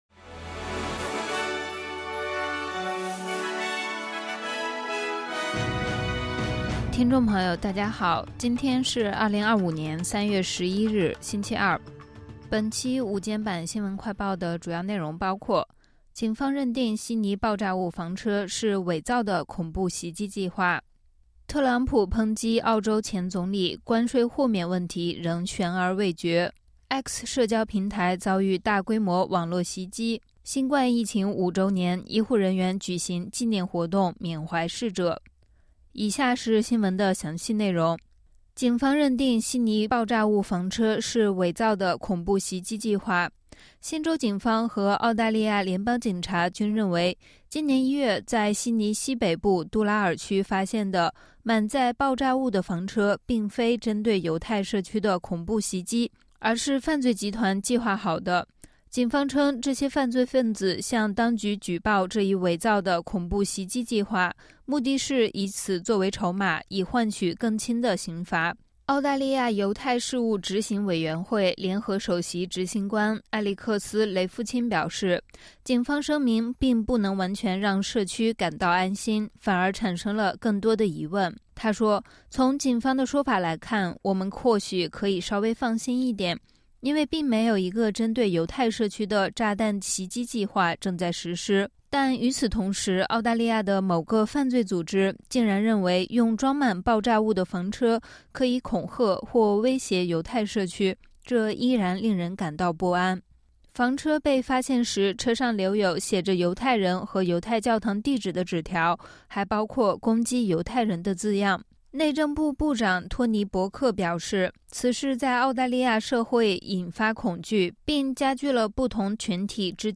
【SBS新闻快报】警方认定悉尼爆炸物房车是“伪造的恐怖袭击计划”